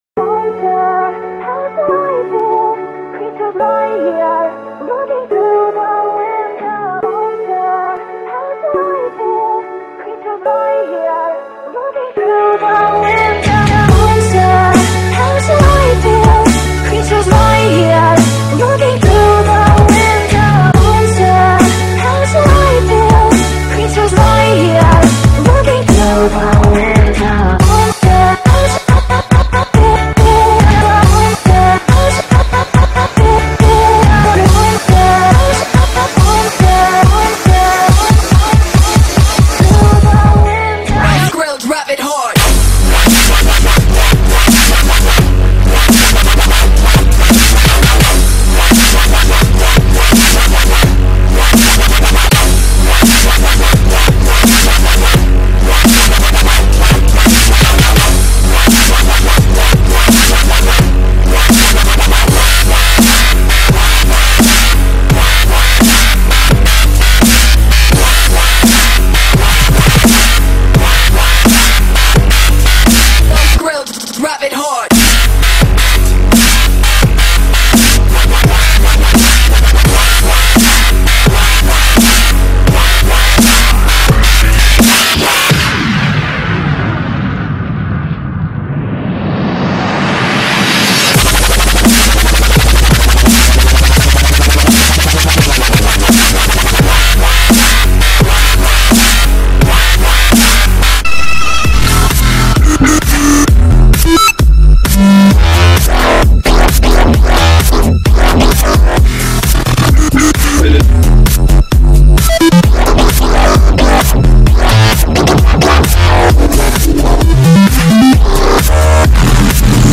DUB STEP--> [6]